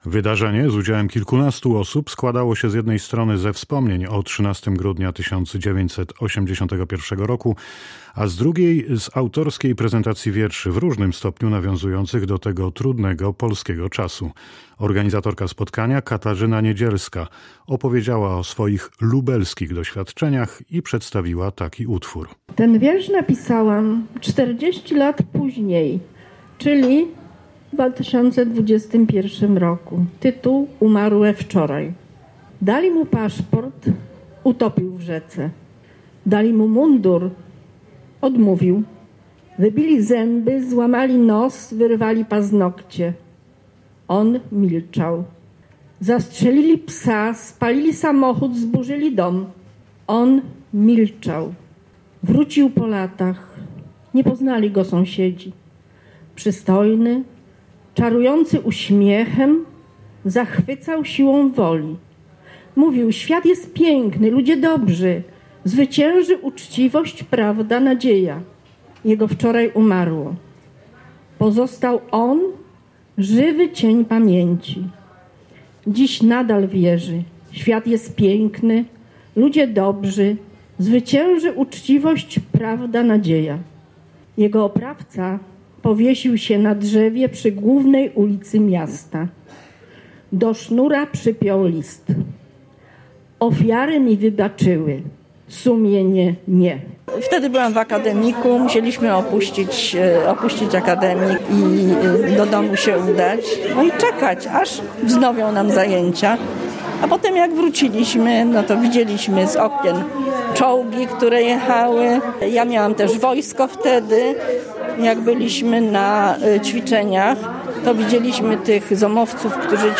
Przy okazji 42. rocznicy wprowadzenia stanu wojennego, w ostatnią środę w Rzeszowie odbyło się spotkanie poetek skupionych w Klubie Promocji Twórczości Literackiej „Tramp-poeta podkarpacki”, działającym w Regionalnym Stowarzyszeniu Twórców Kultury.